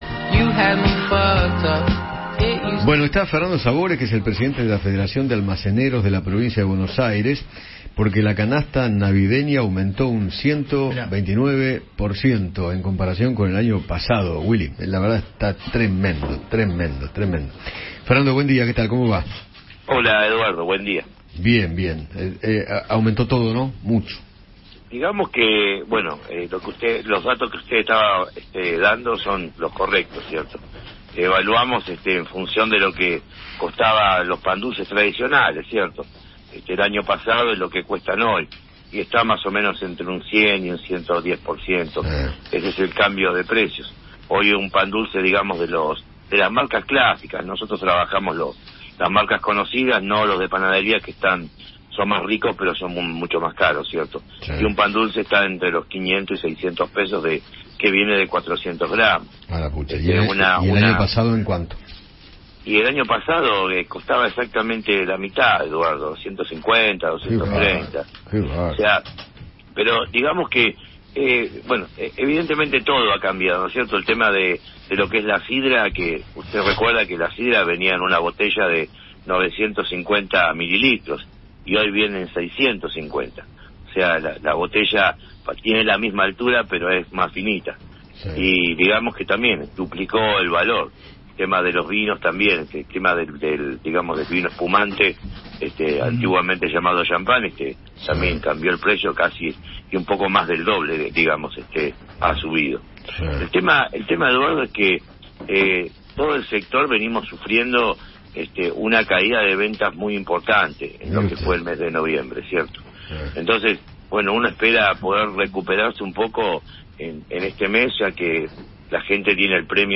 conversó con Eduardo Feinmann sobre el aumento de la canasta navideña en comparación con el año pasado.